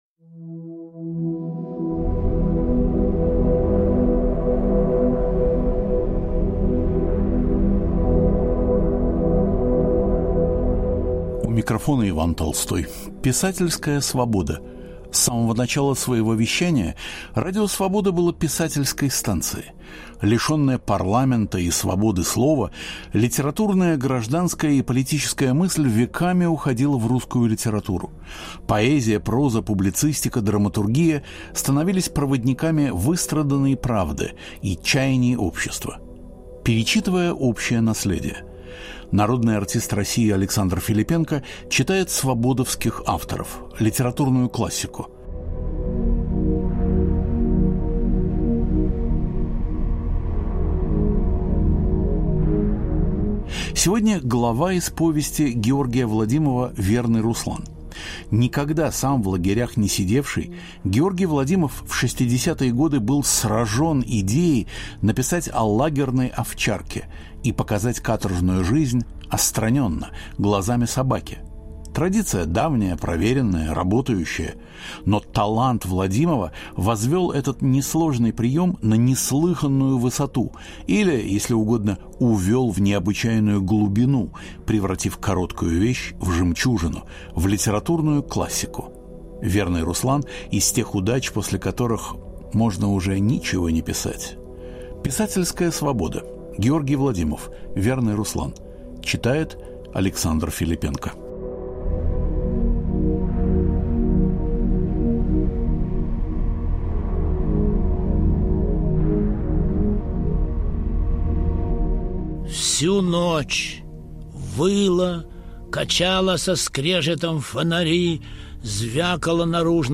Александр Филиппенко читает главу из повести Георгия Владимова "Верный Руслан"